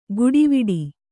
♪ guḍiviḍi